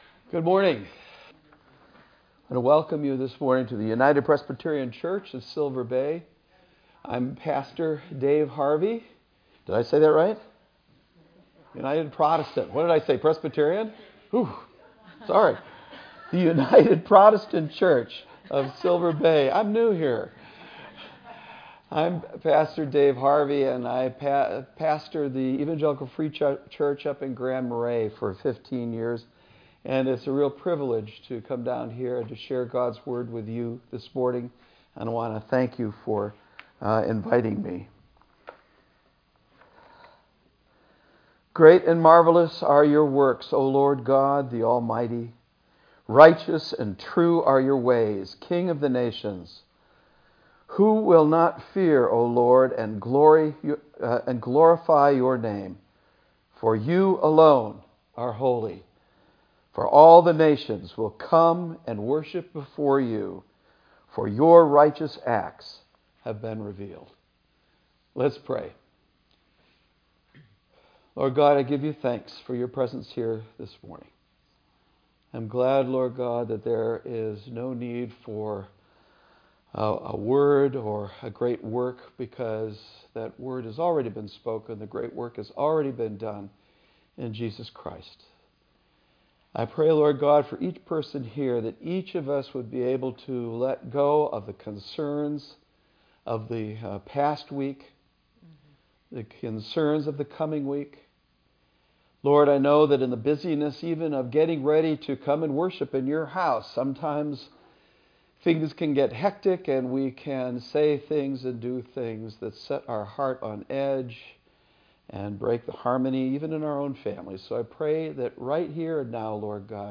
sermon-2.mp3